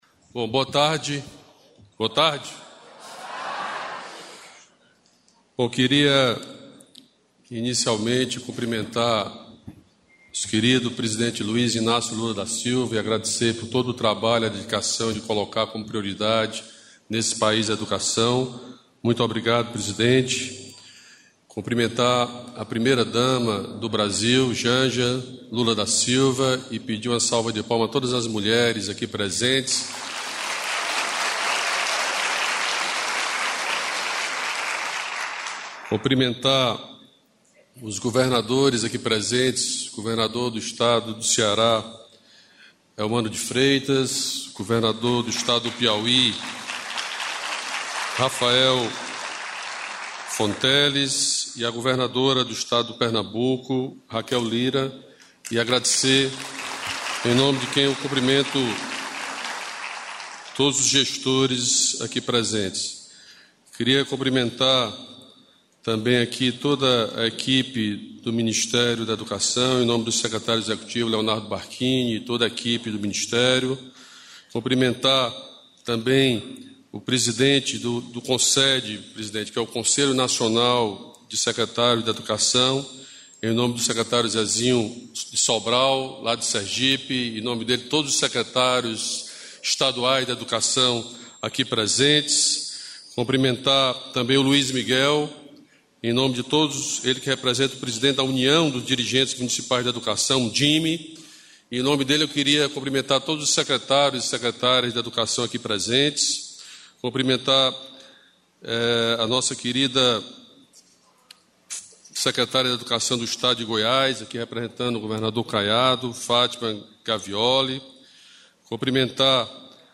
Ouça a fala do ministro do Turismo, Celso Sabino, sobre a agenda nesta terça-feira (12) para apresentar a modernização da rede hoteleira local, melhorias no transporte urbano, ampliação da malha aérea regional e outras ações de logística para o evento global.